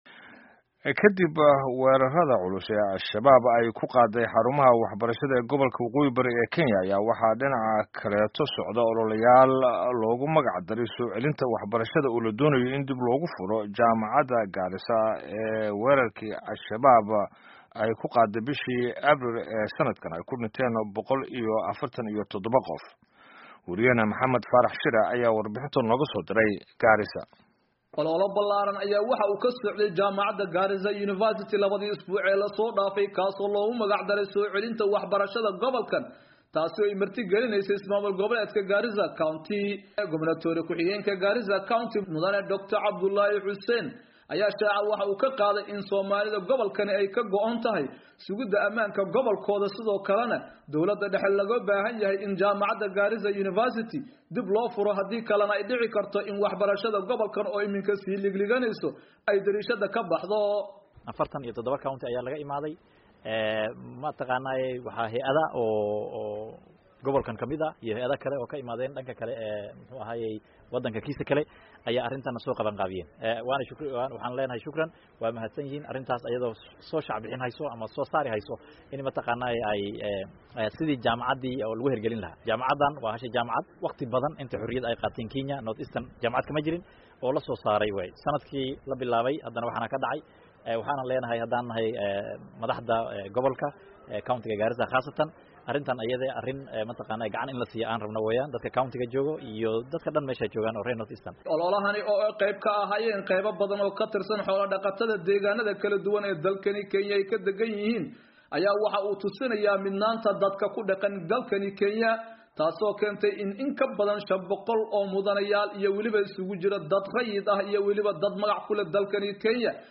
Warbixinta Gaarisa